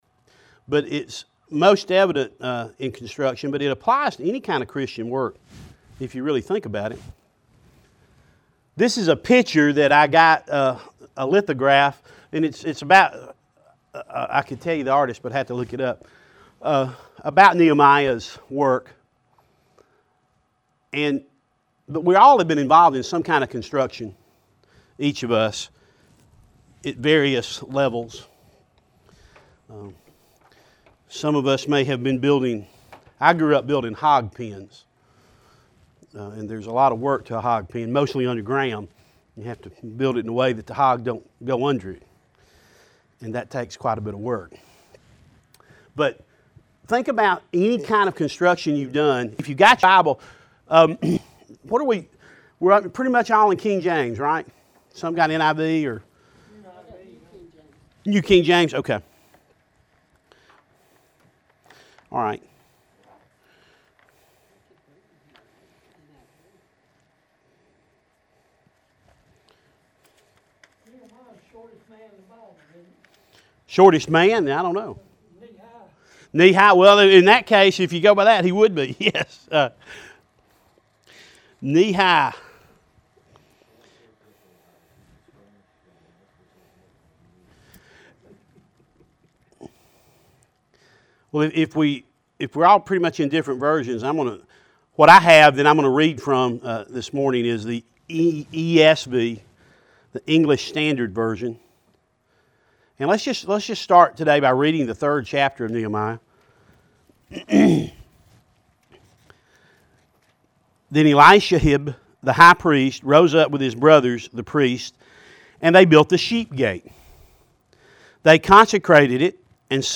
teaches a spring semester class, from the book of Nehemiah at the Bartow Baptist Association.